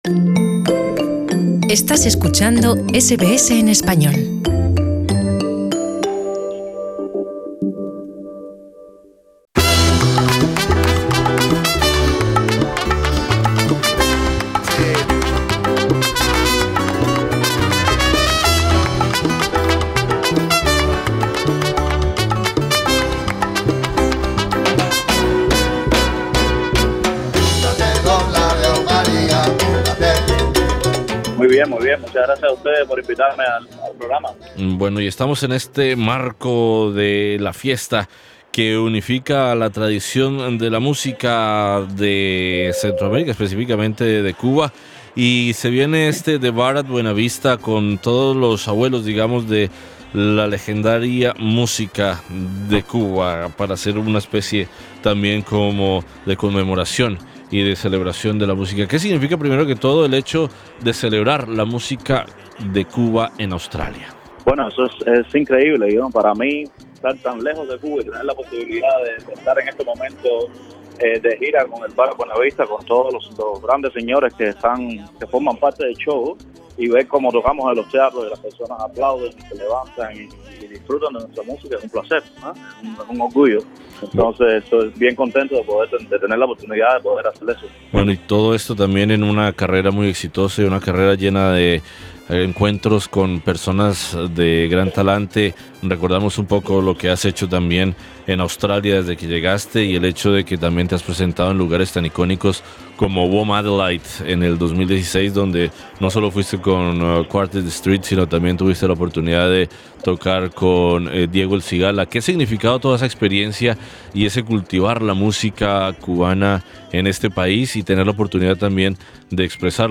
Entrevistamos a uno de los integrantes de este proyecto